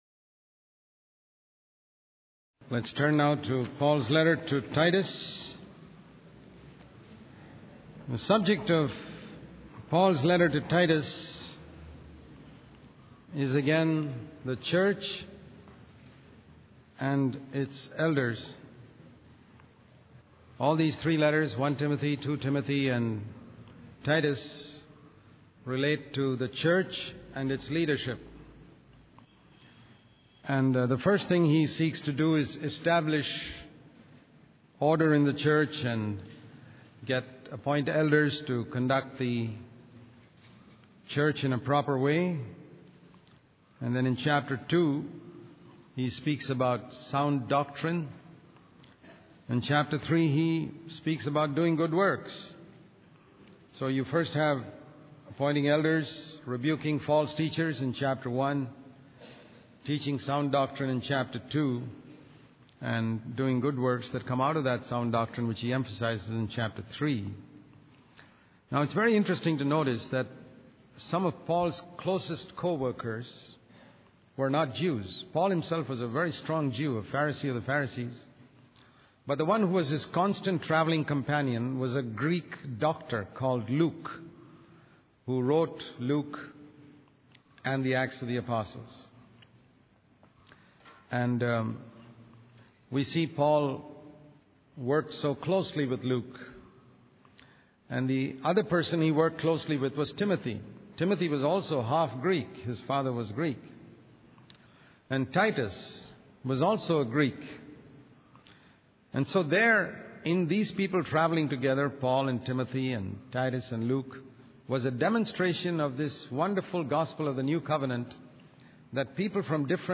In this sermon, the speaker emphasizes the importance of focusing on Jesus and his word rather than getting caught up in trivial arguments and controversies.